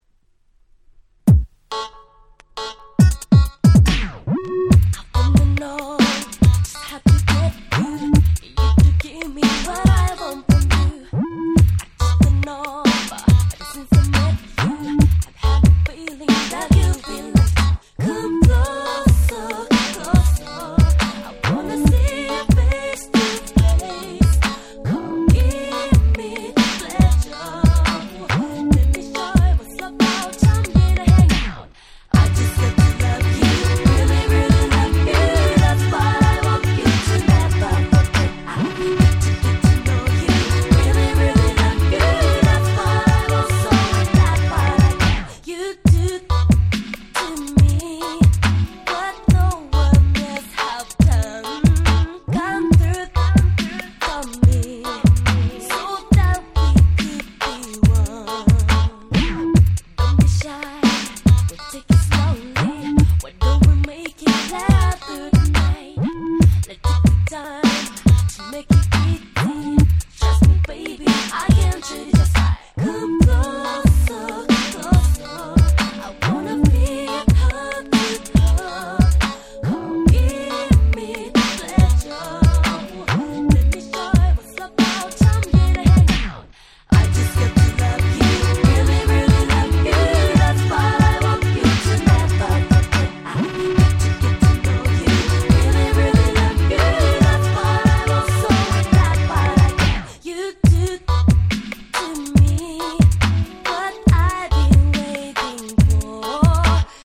00' Nice Japanese R&B !!